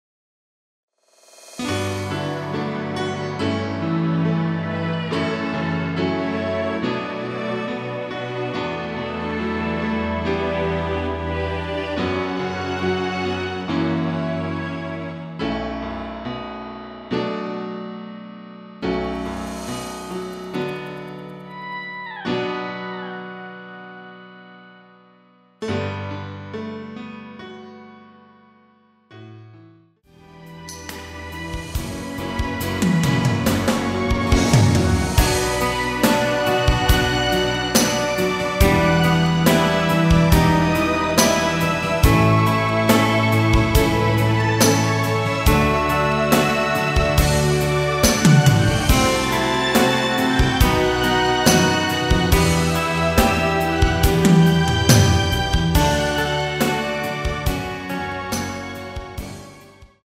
Bm
앞부분30초, 뒷부분30초씩 편집해서 올려 드리고 있습니다.
중간에 음이 끈어지고 다시 나오는 이유는